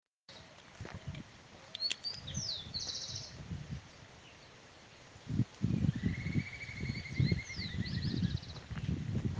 Burlisto Pico Canela (Myiarchus swainsoni)
Nota: la vocalización del burlisto pico canela se encuentra al final del audio.
Nombre en inglés: Swainson´s Flycatcher
Localización detallada: Universidad Nacional de Luján
Condición: Silvestre
Certeza: Observada, Vocalización Grabada